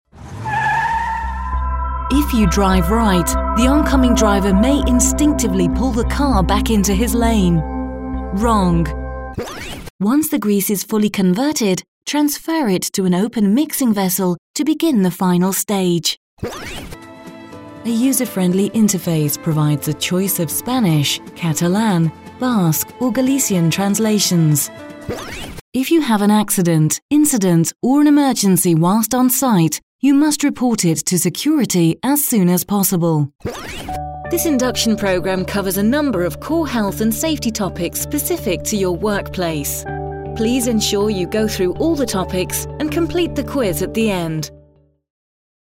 Experienced versatile British female voice over. Home studio. Warm, soothing, luxurious to fun, bright and bubbly.
Sprechprobe: eLearning (Muttersprache):